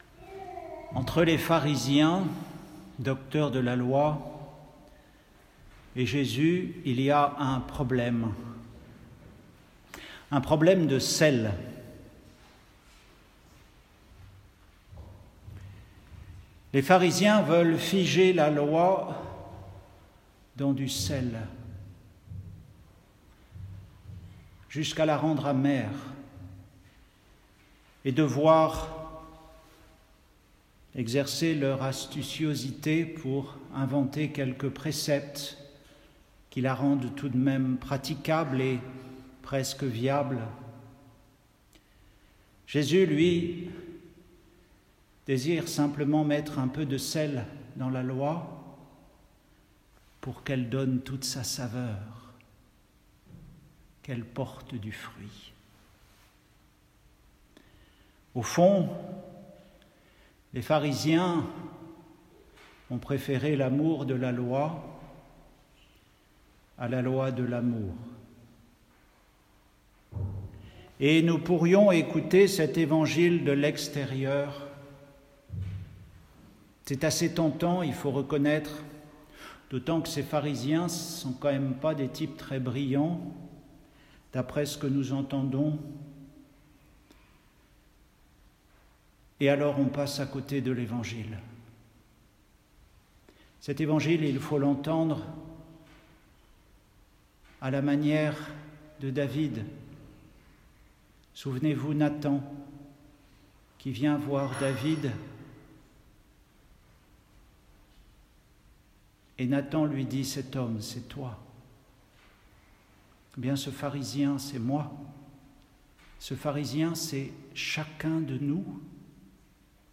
Retrouvez les méditations d’un moine sur les lectures de la messe du jour.